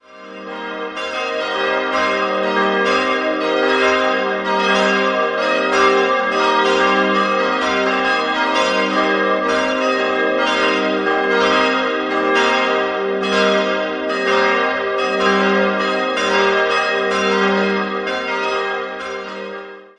Idealquartett: g'-b'-c''-es'' Die beiden kleinen Glocken wurden 1954 von Friedrich Wilhelm Schilling in Heidelberg gegossen, die beiden größeren entstanden ebenfalls in Heidelberg und kamen im Jahr 1980 hinzu.